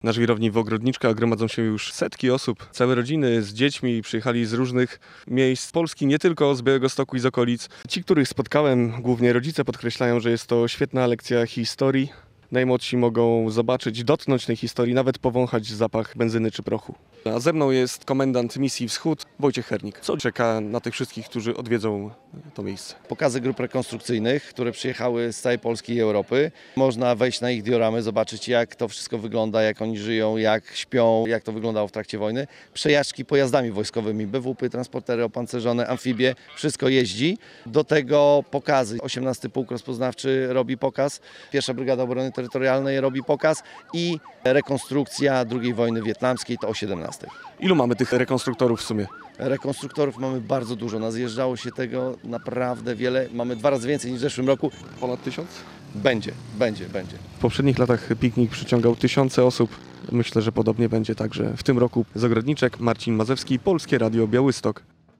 W Ogrodniczkach trwa Piknik Militarny "Misja Wschód" - relacja